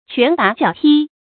拳打腳踢 注音： ㄑㄨㄢˊ ㄉㄚˇ ㄐㄧㄠˇ ㄊㄧ 讀音讀法： 意思解釋： 用拳打，用腳踢。形容痛打。